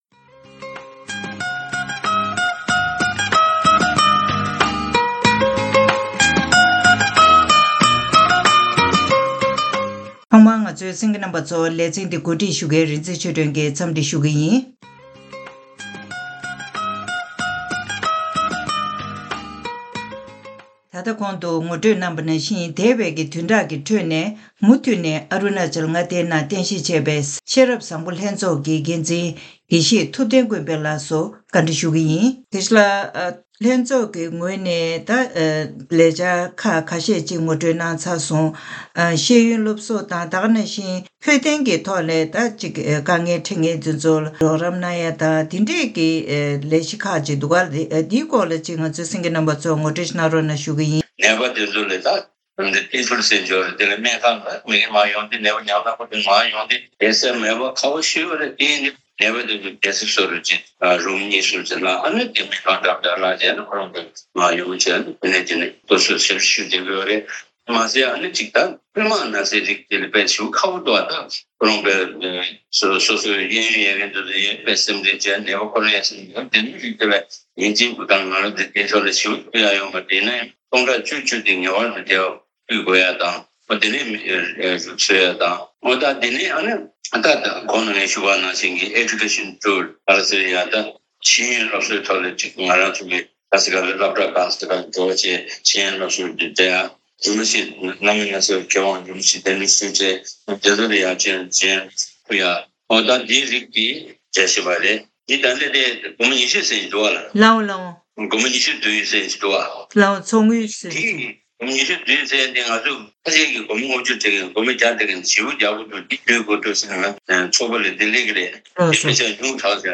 བཀའ་འདྲི་ཞུས་པ་ཞིག་གཤམ་ལ་གསན་གནང་གི་རེད་།